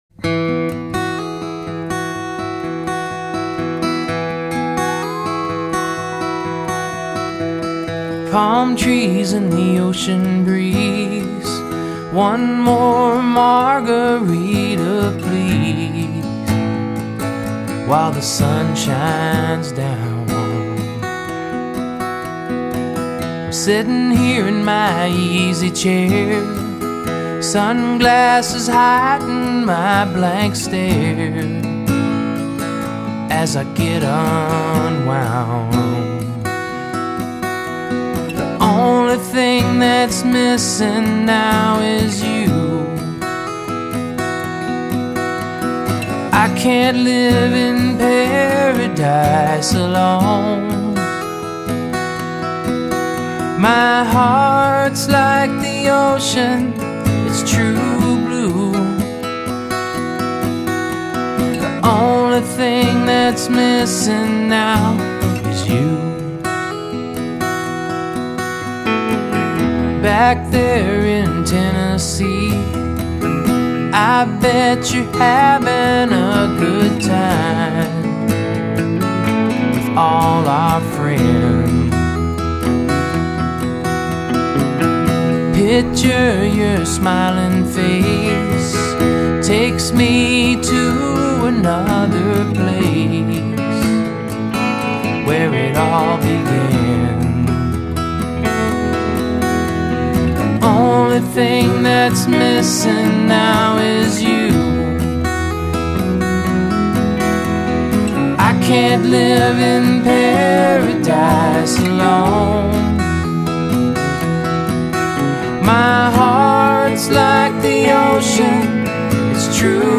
Lead vocal, acoustic guitar
Backing vocals
Electric guitar, backing vocals
Pedal steel